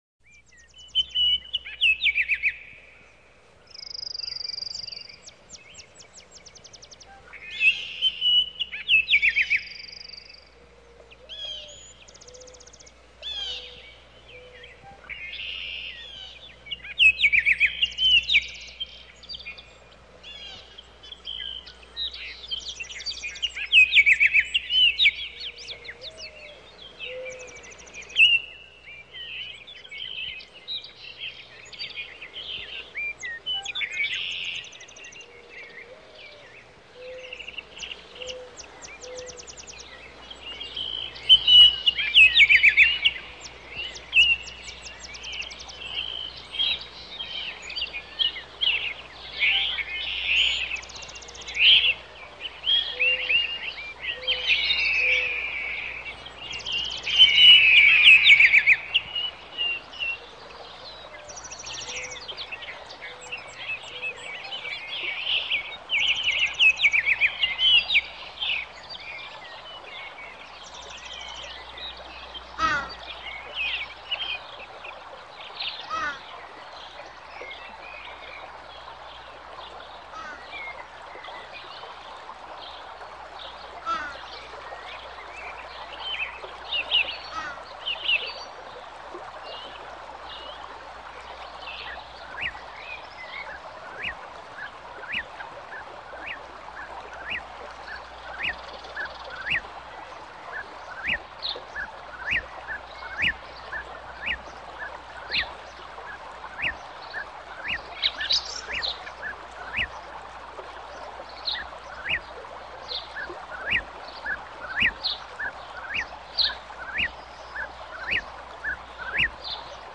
НазваниеЗвуки природы. Лес
_Разное - Звуки природы. Лес(preview).mp3